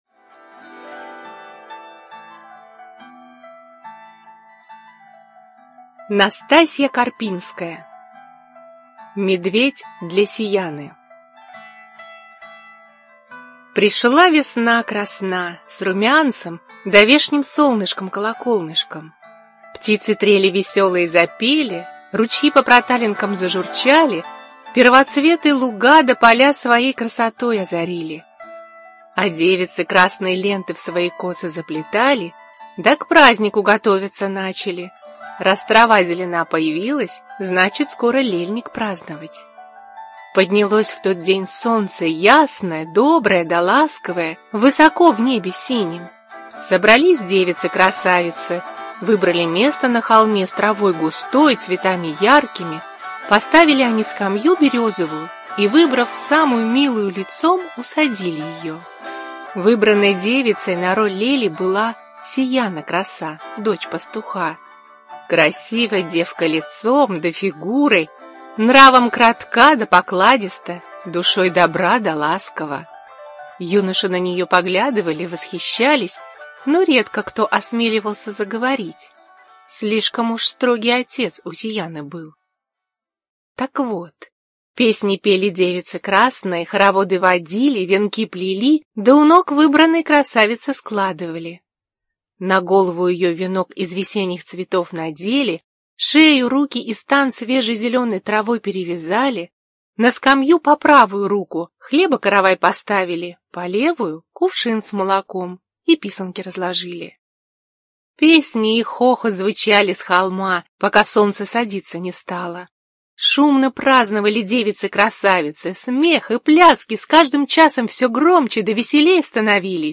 ЖанрДетская литература, Сказки